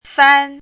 fān
拼音： fān
注音： ㄈㄢ
fan1.mp3